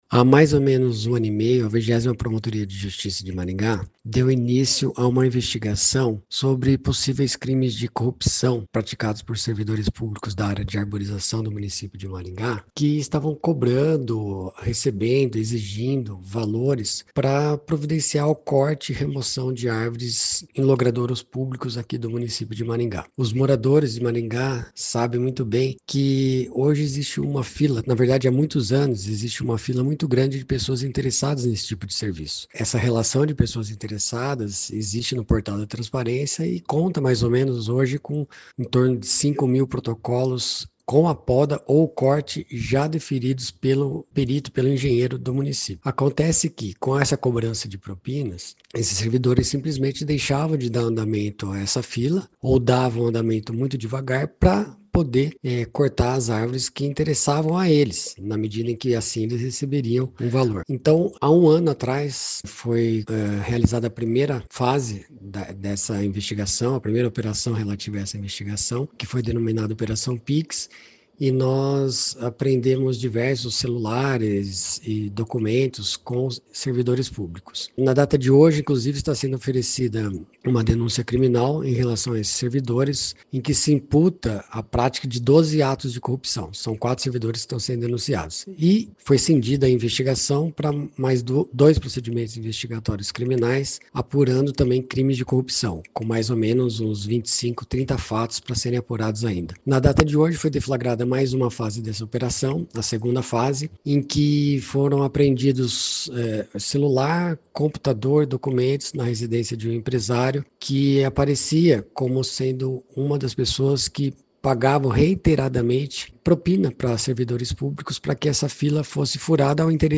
Ouça o que diz o promotor de Justiça Leonardo Vilhena: